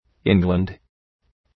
Προφορά
{‘ıŋglənd}